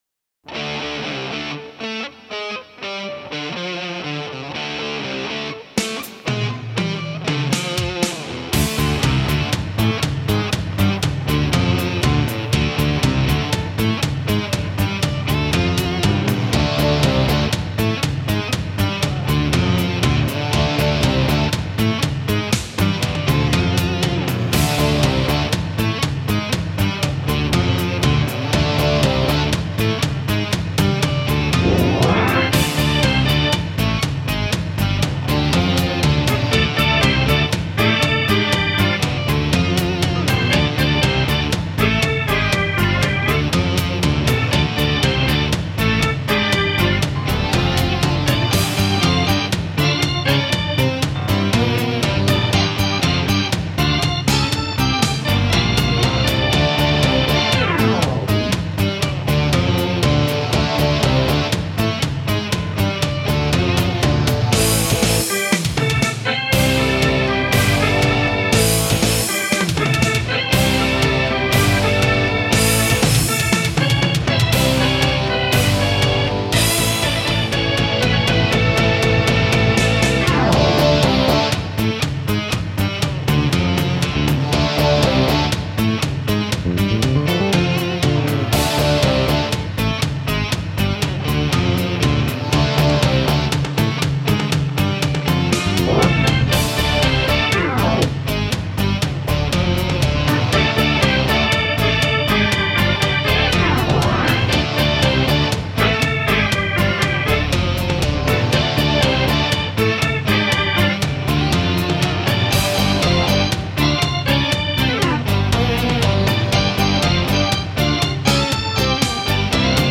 lead guitar